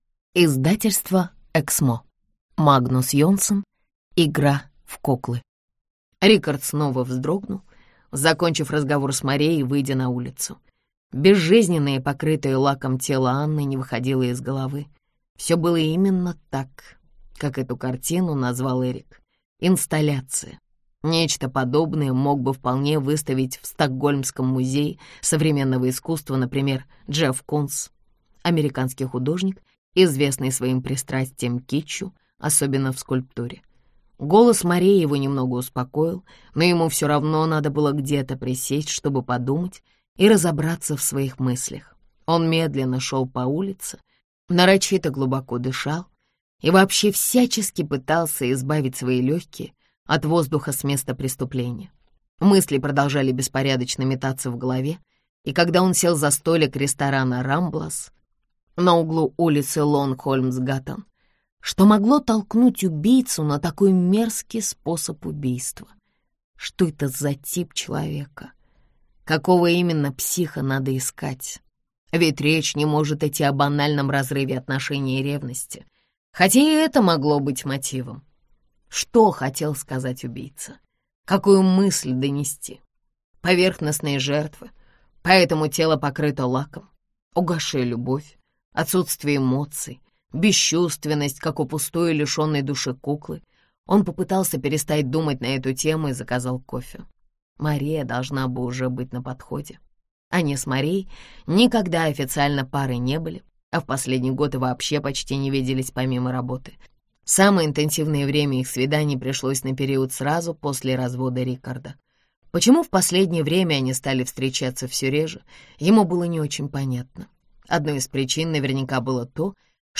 Аудиокнига Игра в куклы | Библиотека аудиокниг